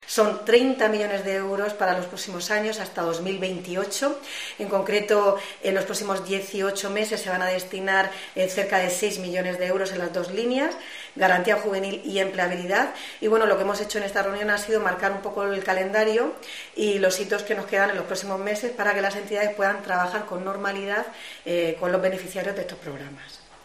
Conchita Ruiz, consejera de Política Social, Familias e Igualdad